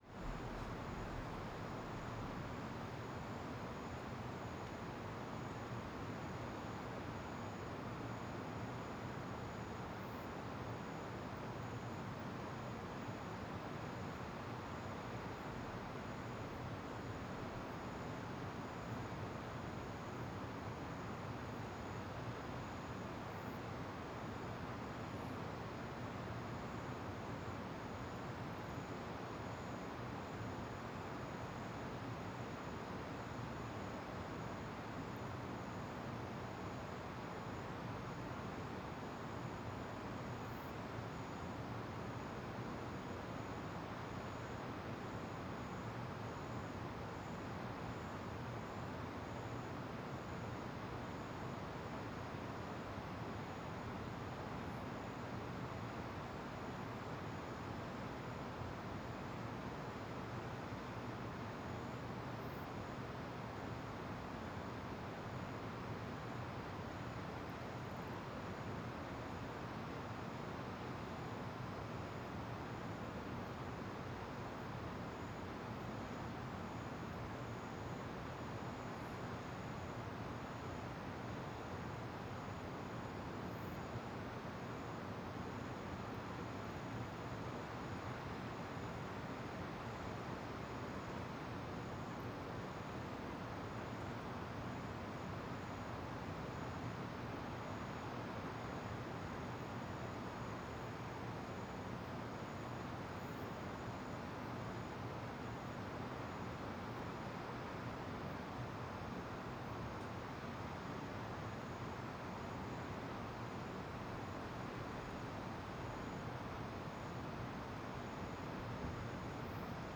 Ambiencia Mirante da Cachoeira Carrossel e o ruido bem grave
Parque Nacional da Chapada dos Veadeiros Alto Paraíso de Goiás Stereo
CSC-05-085-GV - Ambiencia Mirante da Cachoeira Carrossel e o ruido bem grave.wav